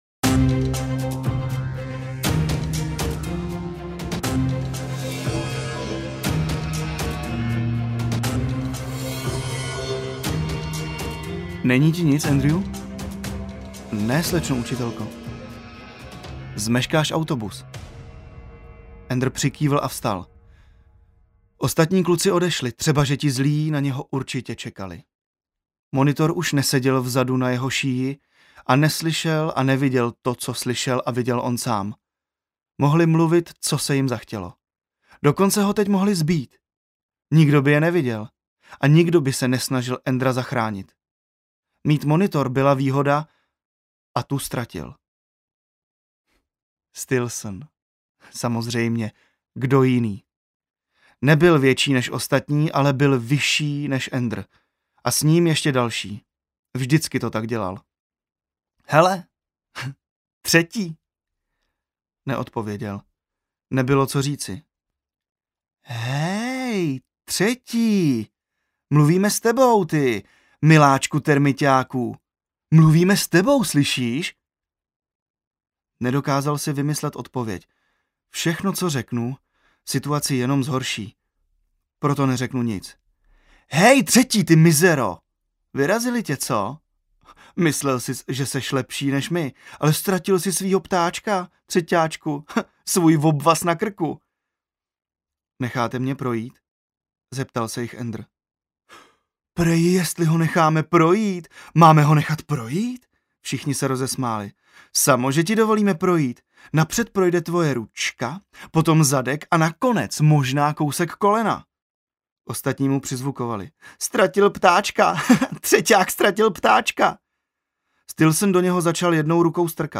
Enderova hra audiokniha
Ukázka z knihy
• InterpretPavel Rímský, Filip Kaňkovský